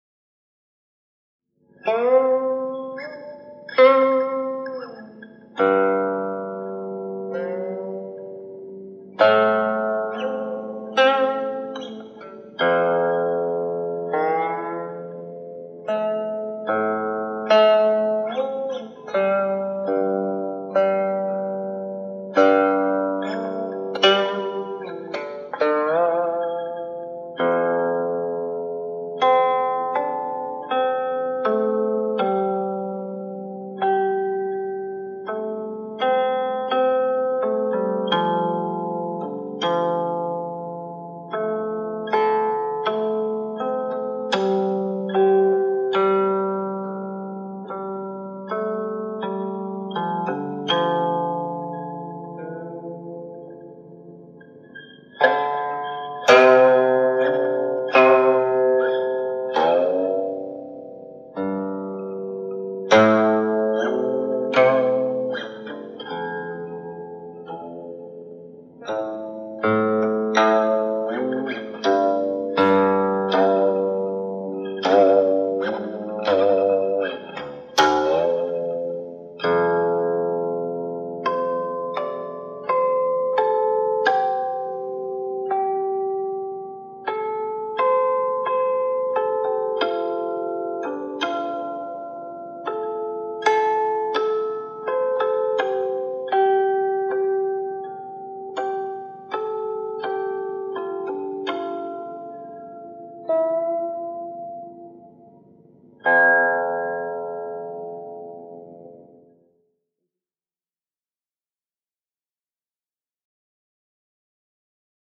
古琴演奏
首首作品或清新飘、或激昂，慢中取韵，韵中含情，情中生景，景中有我，凝神入化，值得珍藏的古琴录音作品。